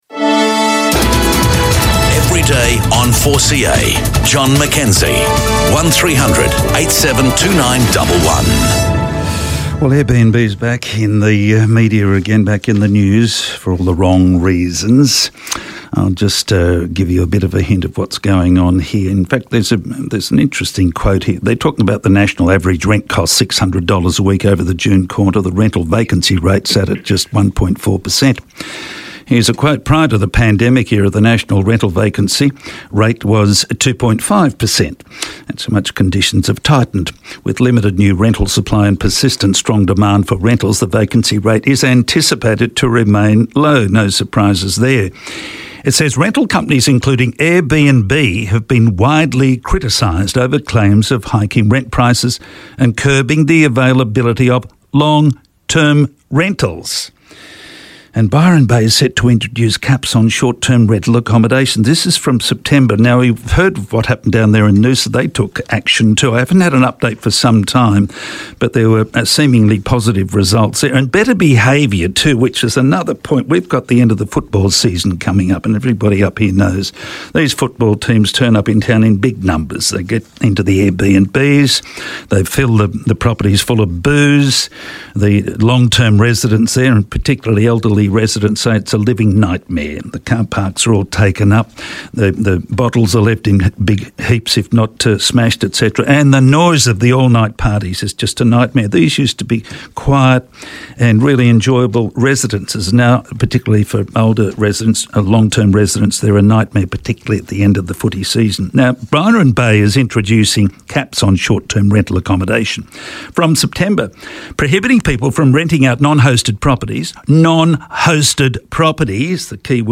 chats with Mayor Michael Lyon, Byron Shire Council, about the need to regulate short-term rental accommodation (such as Airbnb properties) to ensure adequate housing for local, long-term tenants.